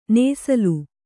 ♪ nēsalu